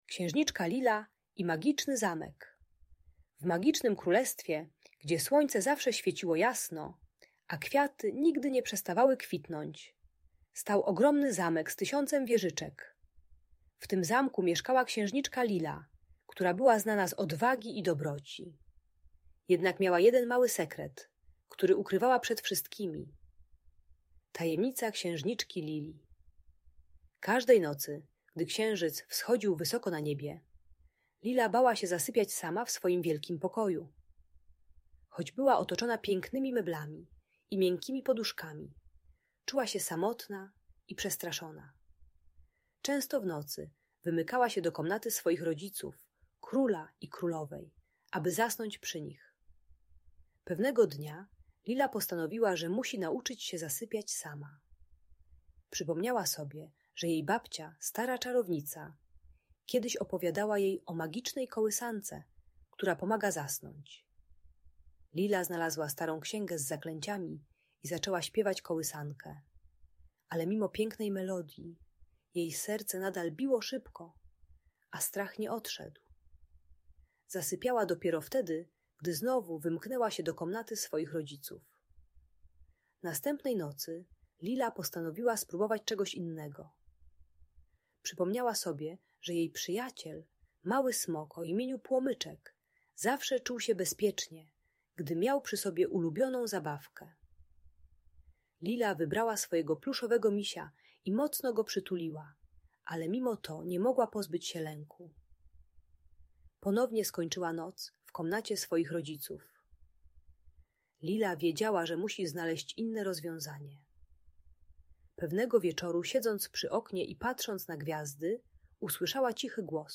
Historia Księżniczki Lili i Magicznego Zamku - Audiobajka